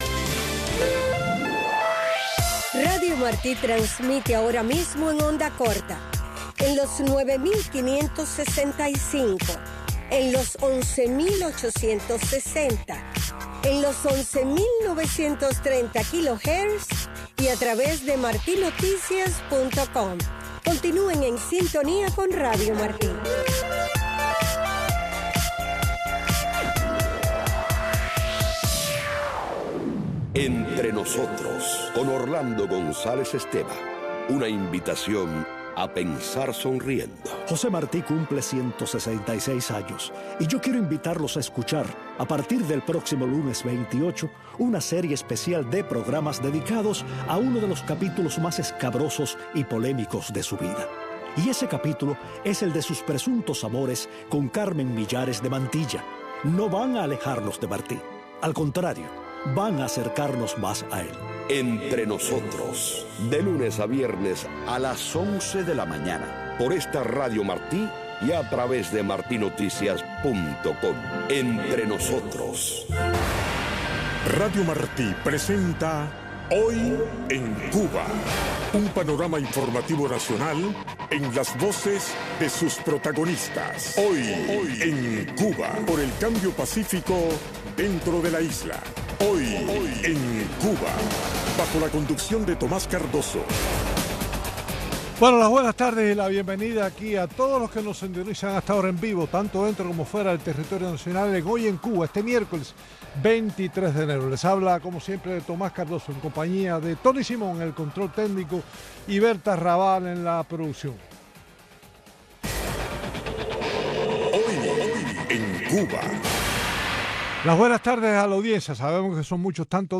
Un espacio informativo con énfasis noticioso en vivo donde se intenta ofrecer un variado flujo de información sobre Cuba, tanto desde la isla, así como desde el exterior.